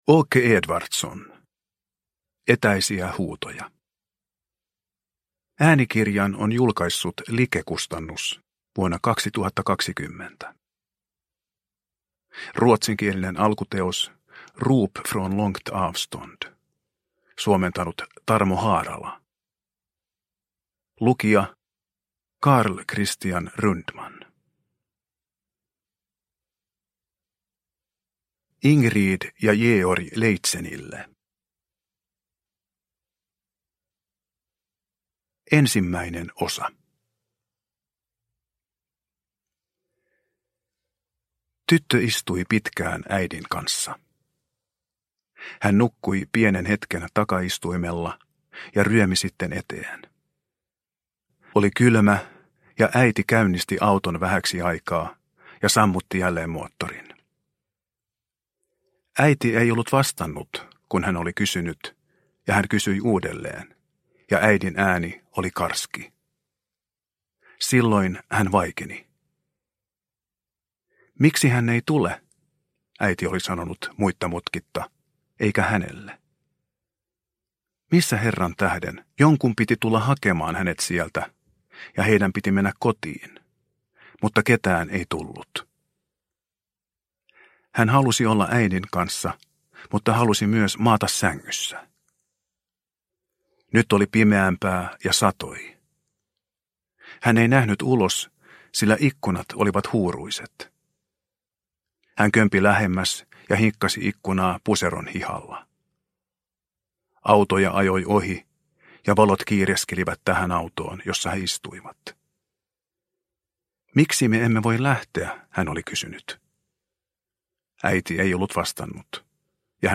Etäisiä huutoja – Ljudbok – Laddas ner